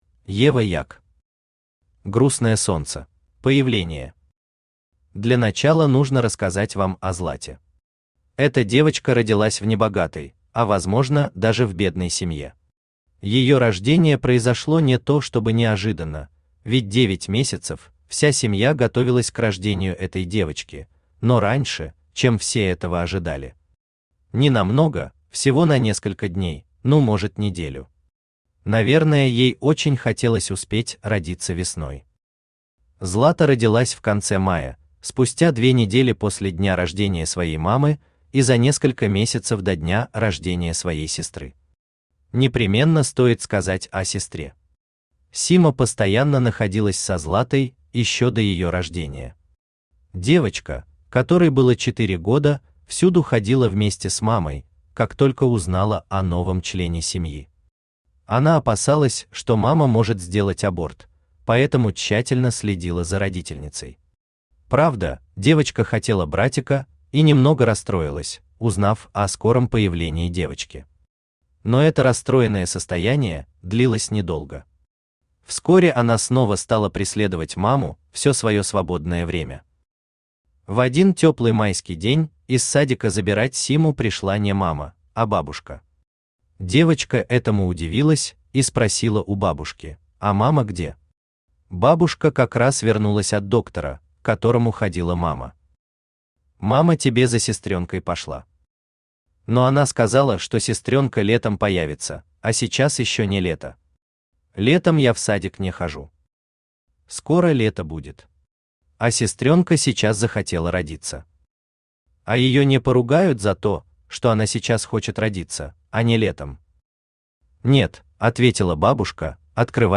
Аудиокнига Грустное Солнце | Библиотека аудиокниг
Aудиокнига Грустное Солнце Автор Ева Як Читает аудиокнигу Авточтец ЛитРес.